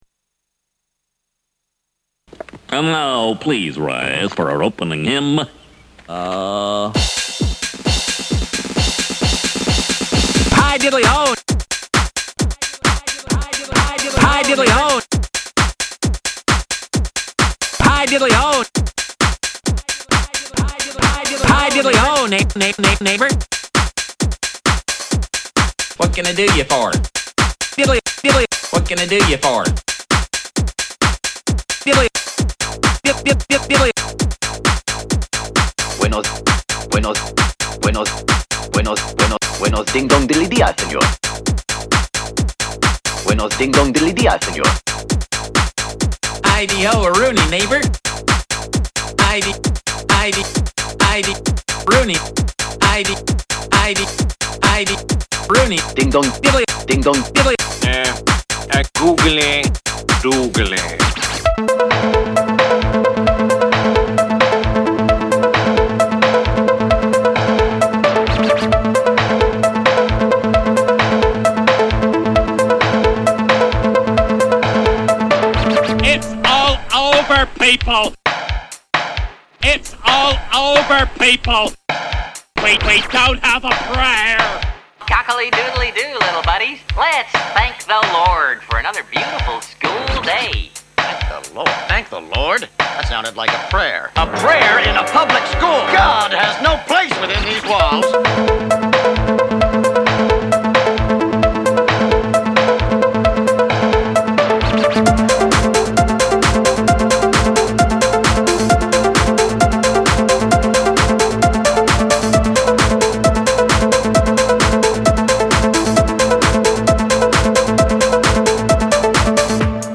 dance track
To download the track, which is stuffed full of Ned's greatest sayings, click here.